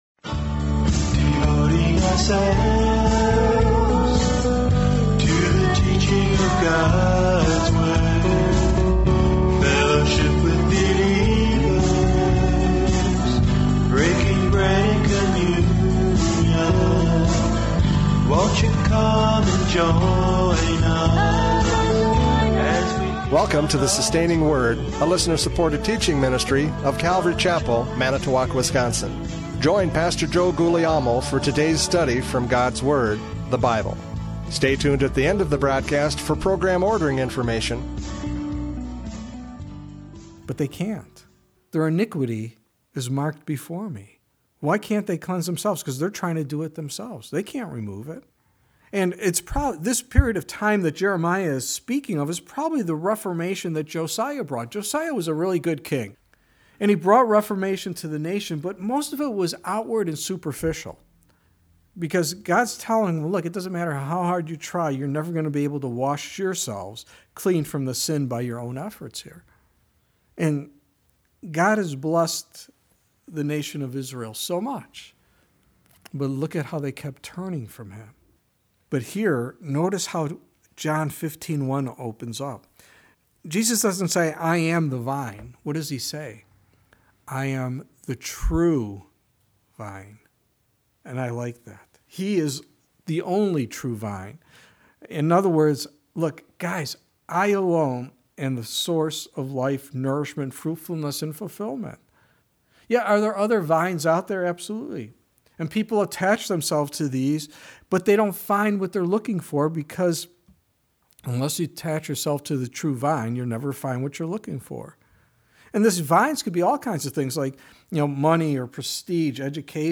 John 15:1-11 Service Type: Radio Programs « John 15:1-11 “I Am the Vine!”